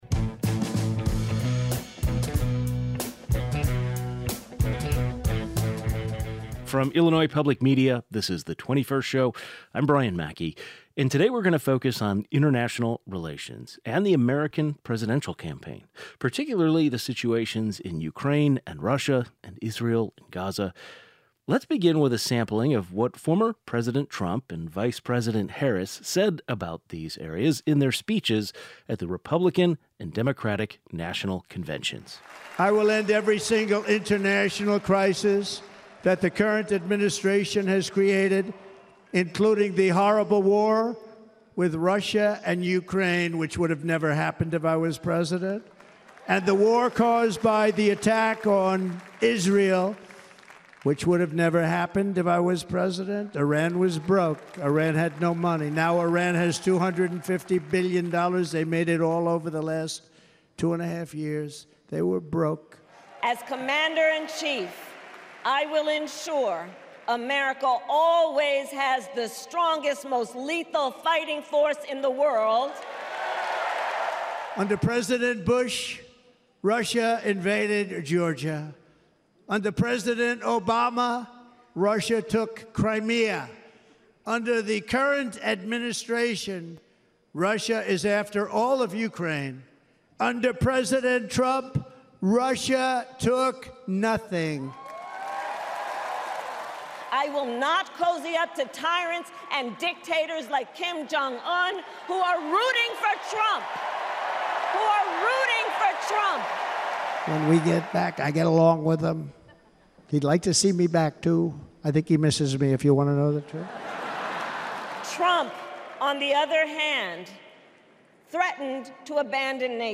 A geopolitics expert joins the 21st to discuss the latest developements in both conflicts and how it relates to the election in the U.S.